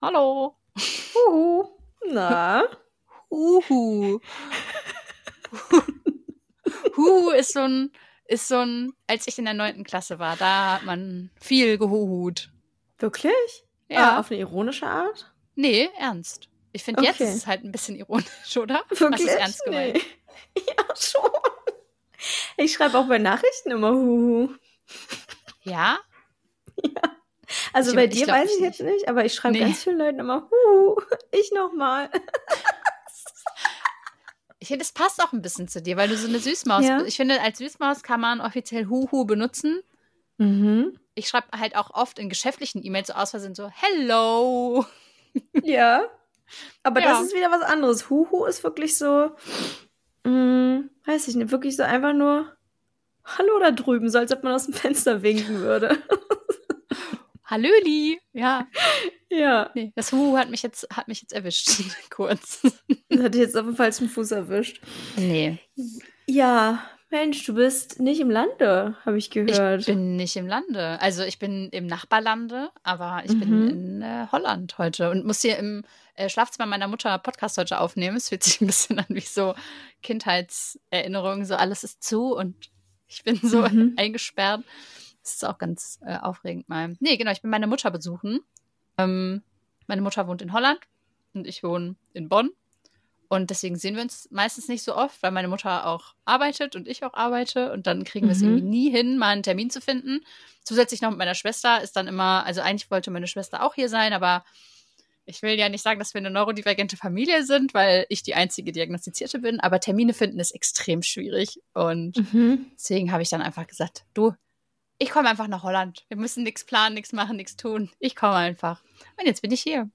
Für gegenseitiges Unterbrechen, wilde Anekdoten, blumige Gefühlsbeschreibungen und wissenschaftlichen Zuckerguss zum Thema ADHS und Neurodiversität jeder Art empfehlen wir uns selbst. Abseits von allen Zappelphilipp-Klischees erzählen wir, wie es sich wirklich anfühlt, mit einem neurodivergenten Kopf durch die Gegend zu rennen.